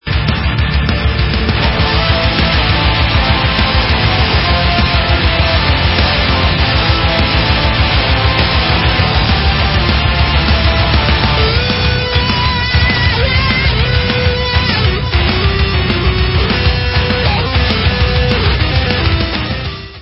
sledovat novinky v oddělení Rock/Progressive